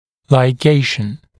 [laɪˈgeɪʃn][лайˈгейшн]лигирование, наложение лигатуры